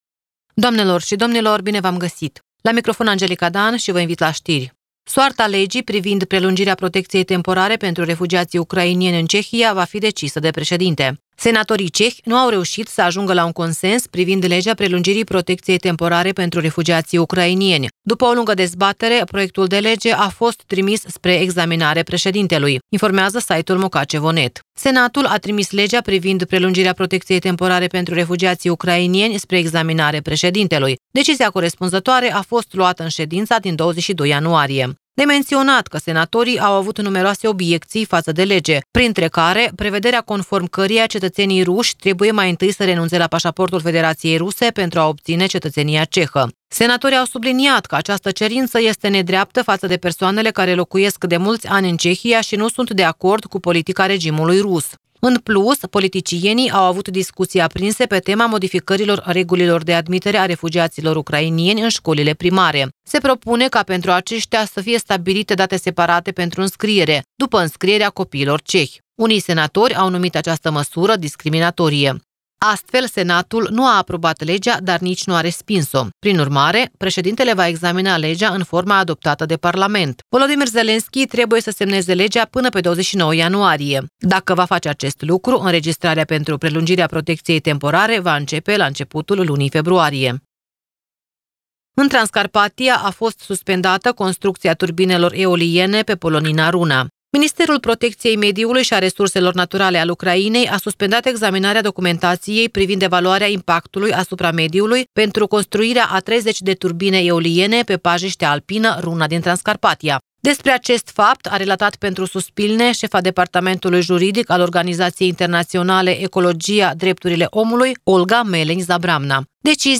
Vă invit la ştiri.